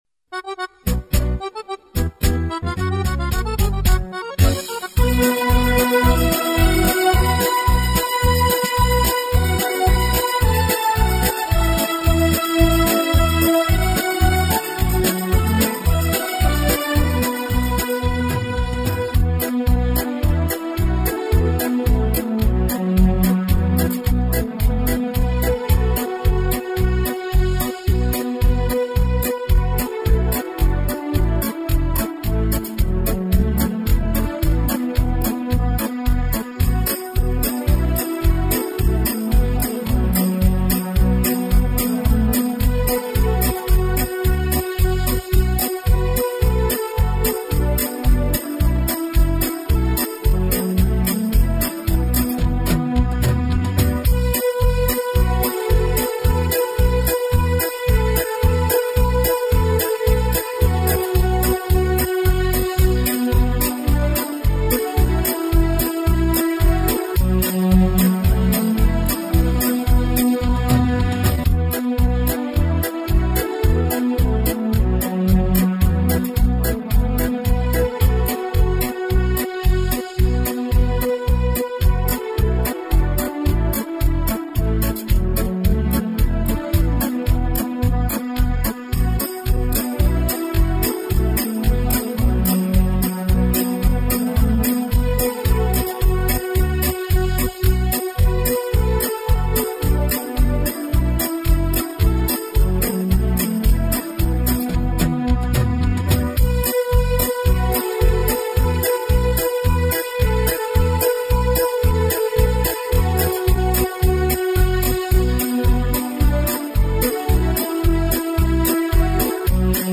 无 调式 : D 曲类